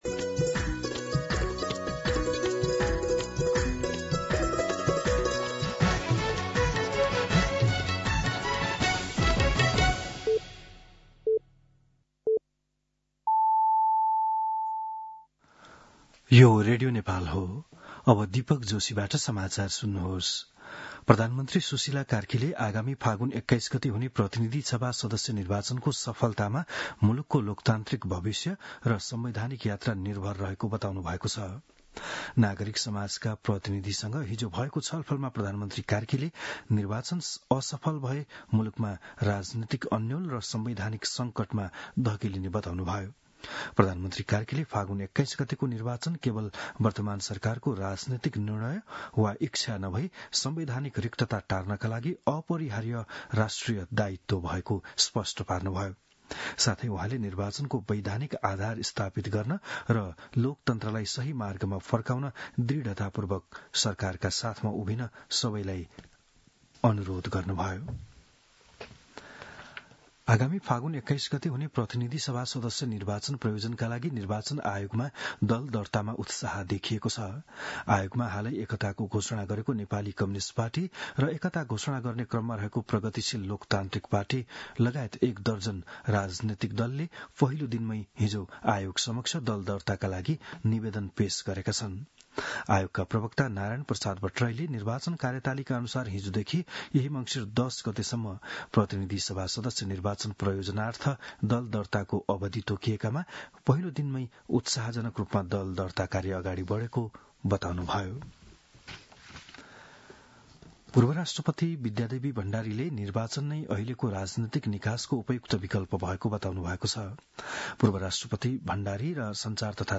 बिहान ११ बजेको नेपाली समाचार : १ मंसिर , २०८२